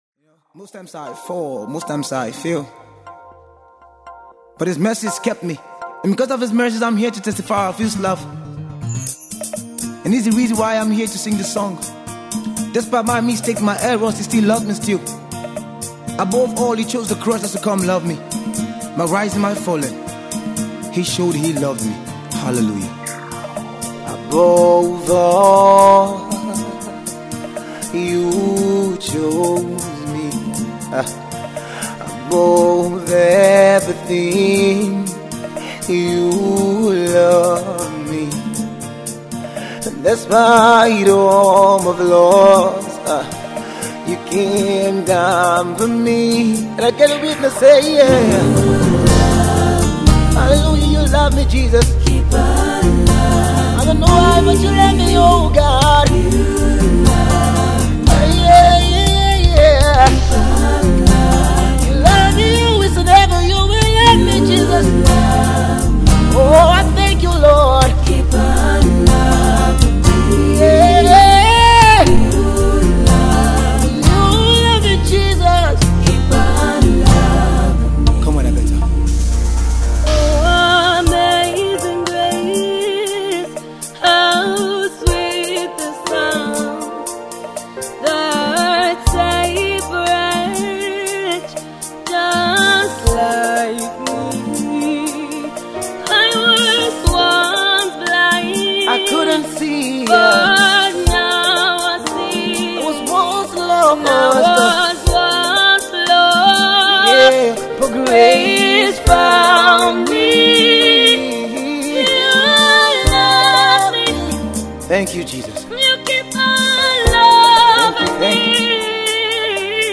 an Abuja based gospel singer and spoken artist